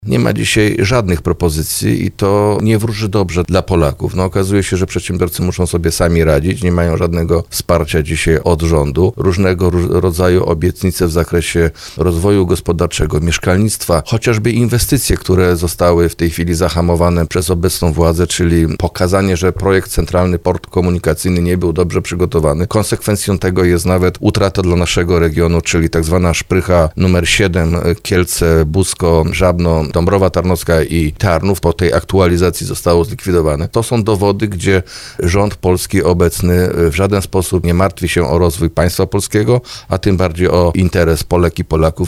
Poseł Wiesław Krajewski w Słowo za Słowo na antenie RDN Małopolska wskazywał, że jednym z powodów grupowych zwolnień w państwowym gigancie jest zaniedbanie przez rządzących inwestycji, także w sektorze kolejowym.